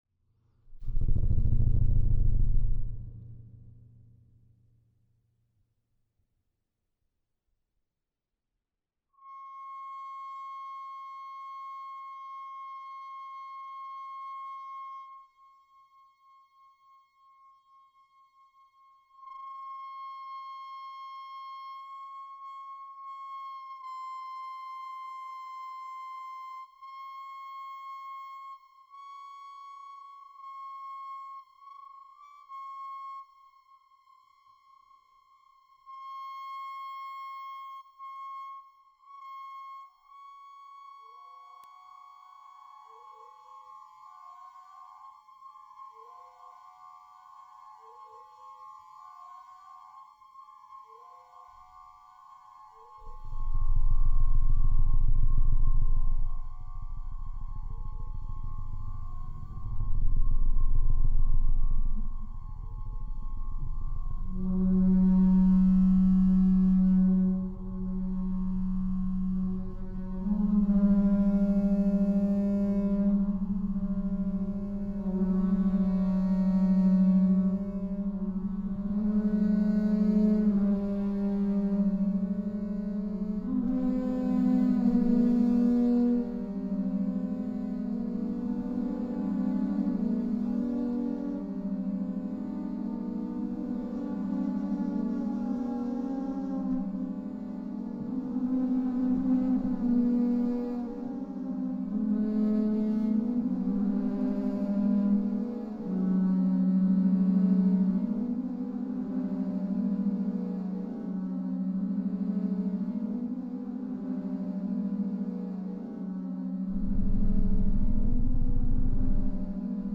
Je nach geöffnetem Loch entsteht ein anderer Grundton mit anderen Obertönen. Der tiefste Grundton ist D-2, das sind 4,7 Hz.
A-2 liegt zwei Oktaven tiefer als der tiefste Klavierton.
Wir spielen im Sinne von instant composing , aus der Situation und dem Moment heraus, möglichst ohne Absprachen.